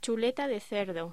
Locución: Chuleta de cerdo